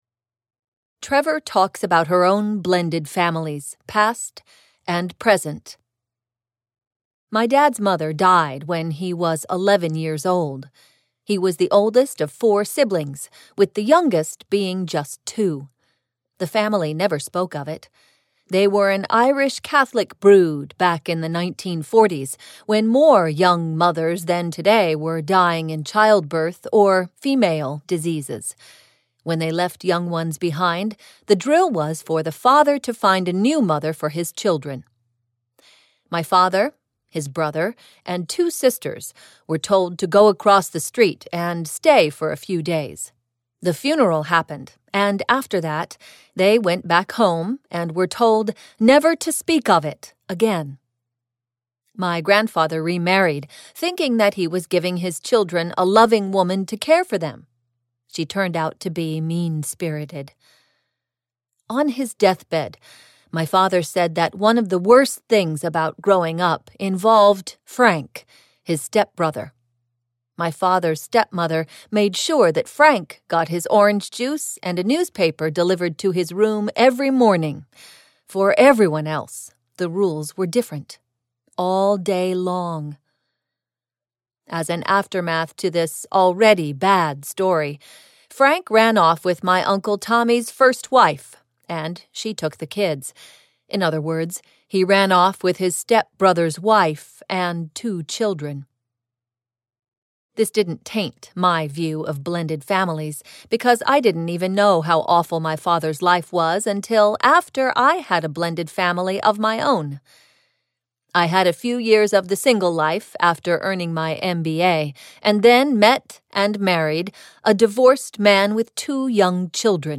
Blending Families - Merging Households with Kids 8-18 - Vibrance Press Audiobooks - Vibrance Press Audiobooks
SAMPLE-Blending-Families.mp3